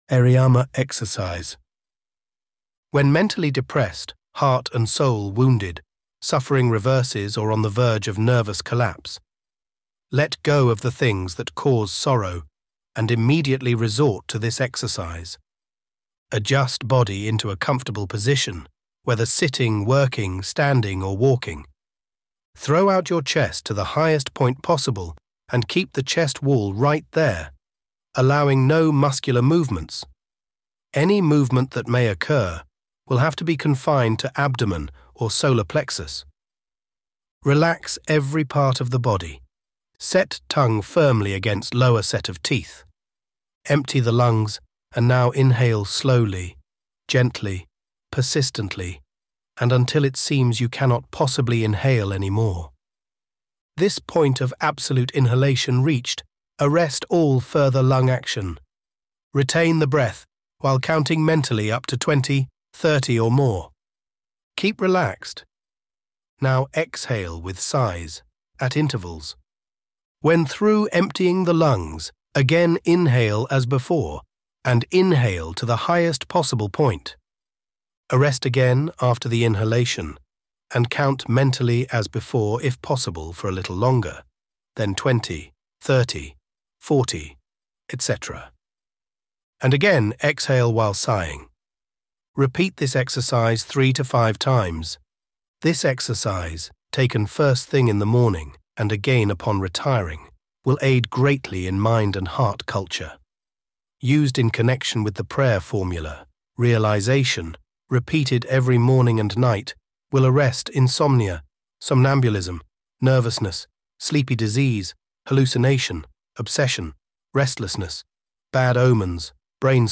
(spoken by Elevenlabs George)